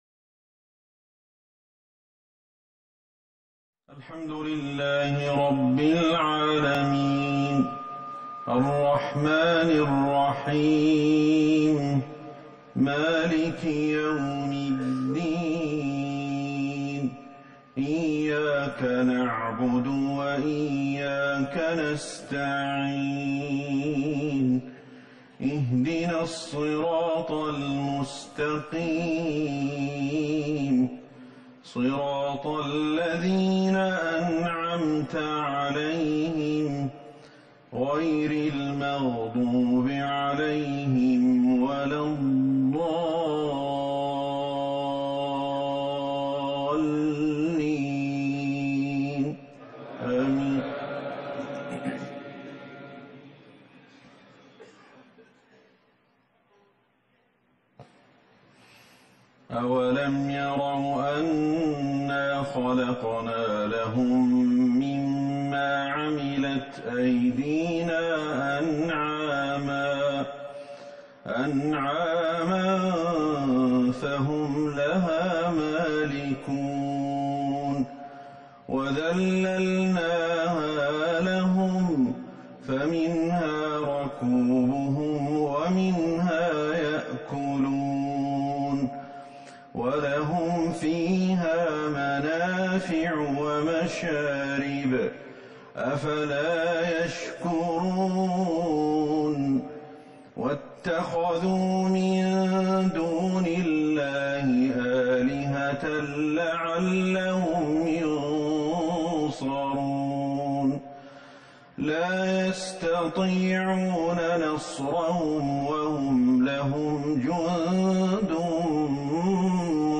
صلاة المغرب ١٤ جمادى الاخرة ١٤٤١هـ من سورة يس | Maghrib prayer 8-2-2020 from Surah Yasin > 1441 هـ > الفروض